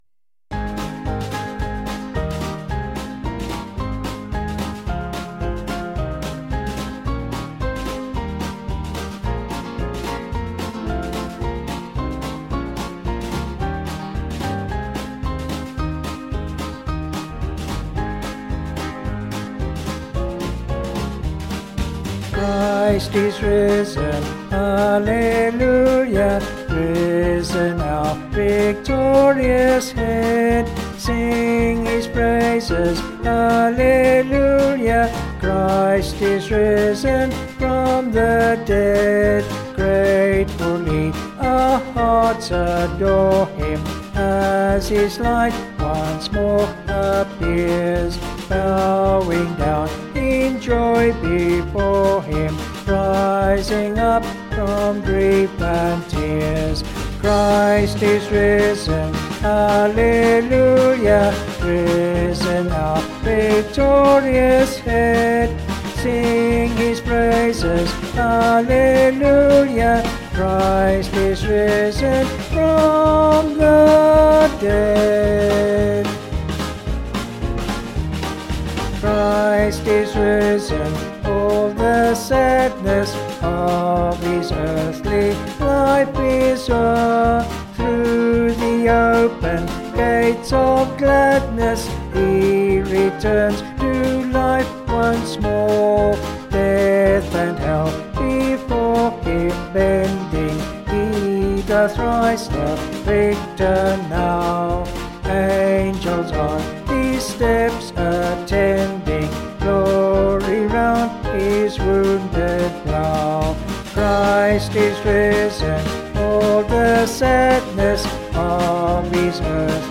Vocals and Band   266.7kb Sung Lyrics